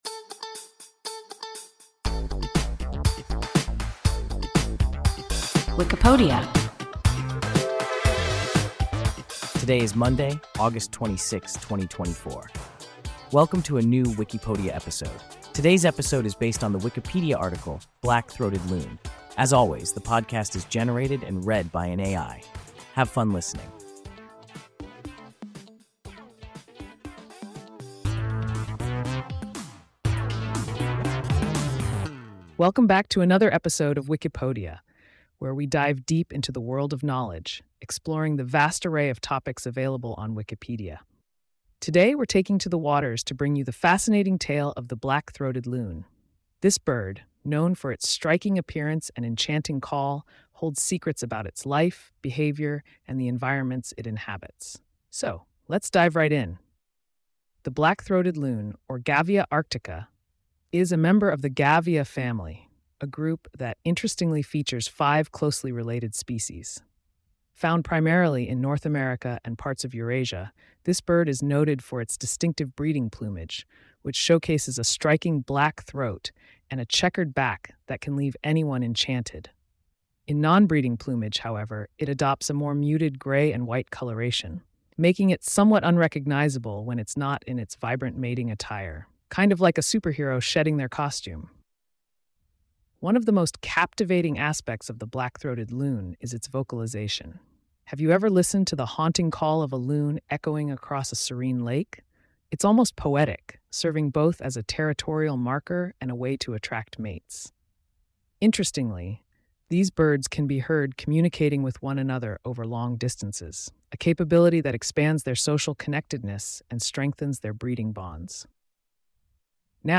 Black-throated loon – WIKIPODIA – ein KI Podcast